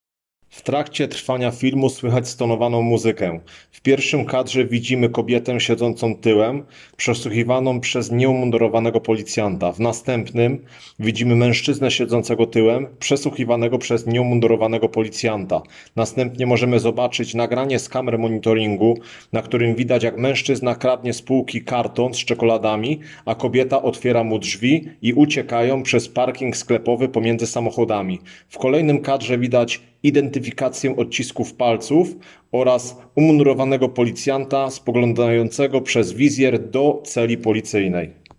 Nagranie audio Audiodeskrypcja filmu.